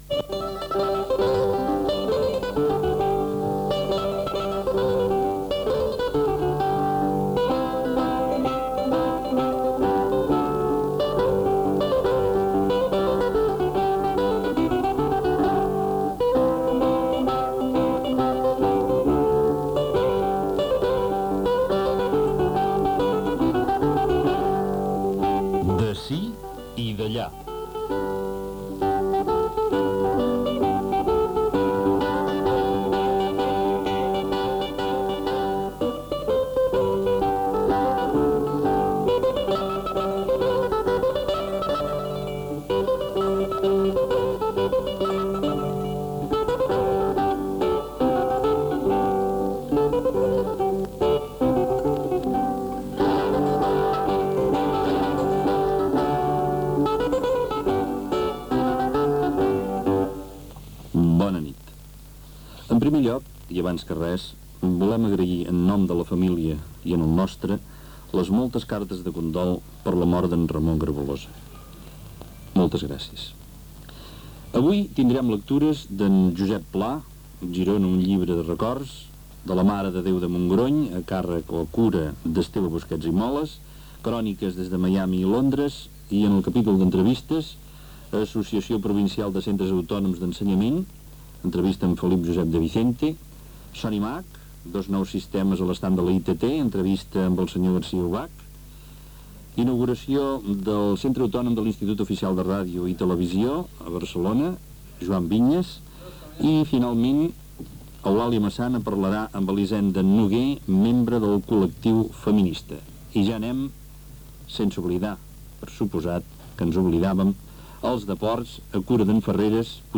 Careta del programa
Gènere radiofònic Info-entreteniment